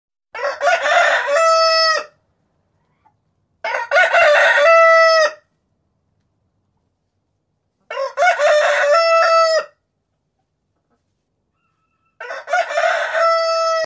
Suoneria Sveglia Gallo
Categoria Sveglia